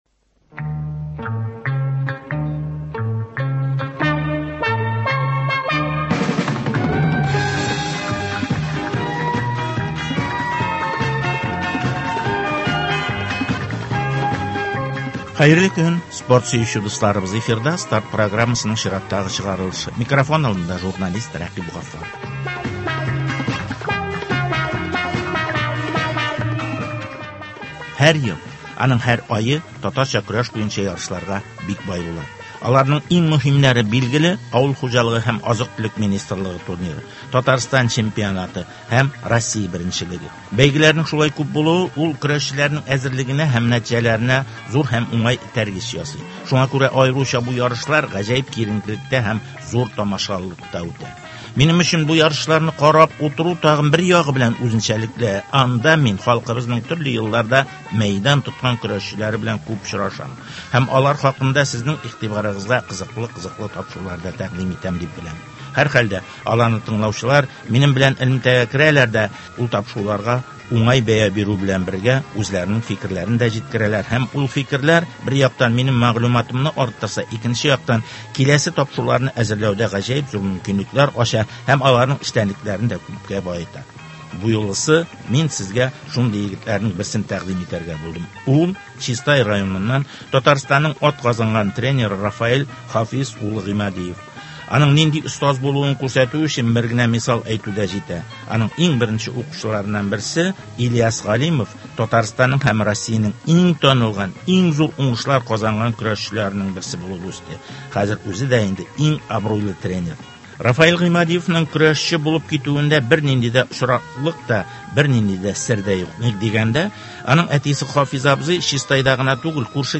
аларның нәселенең көрәшне яңа баскычка күтәрүдәге роле хакында әңгәмә.